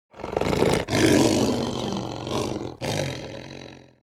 Lion Snarl Efeito Sonoro: Soundboard Botão
Lion Snarl Botão de Som